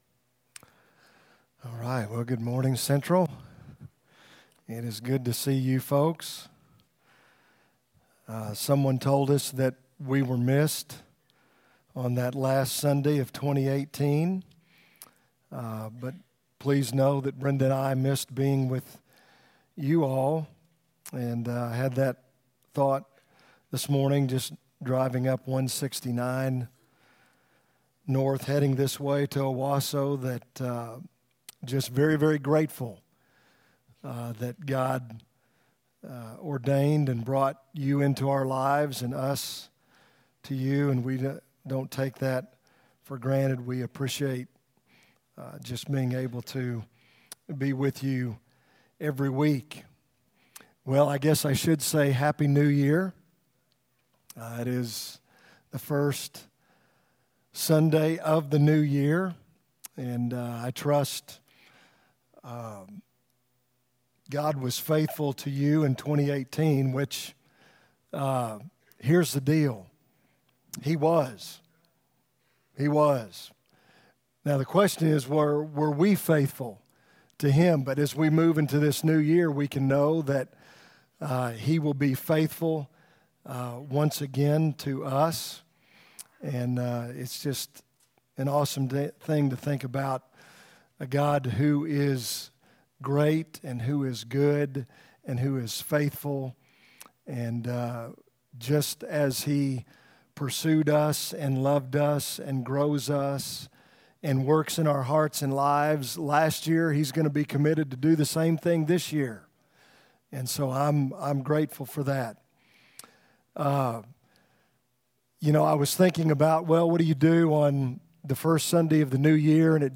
From Series: "2019 Sermons"
Our 2019 sermons that aren't part of a "series".